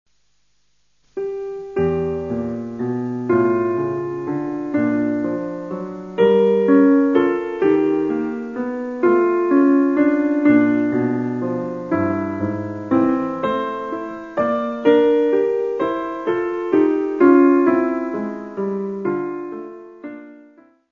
Music Category/Genre:  Classical Music